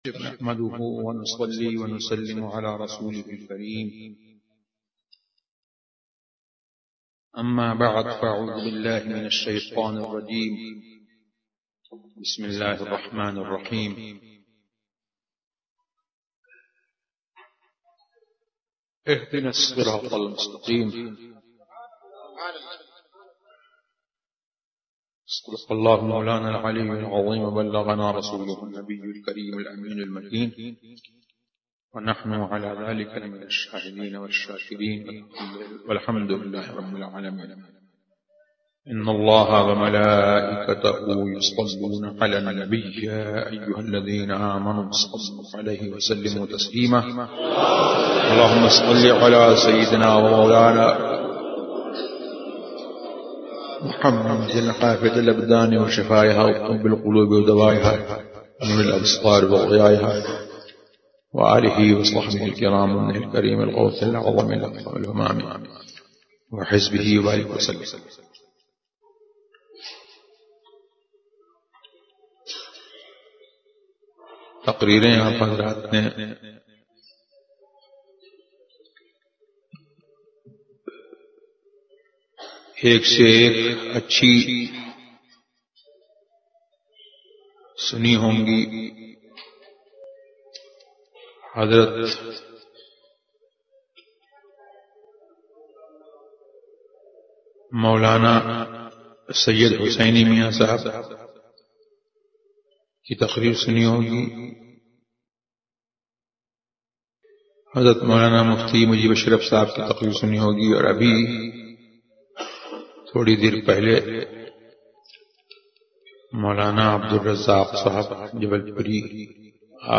تقاریر